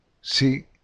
When it is accompanied by the vowels “i” and “e”, its sound is identical to that of the syllables formed with the letter S: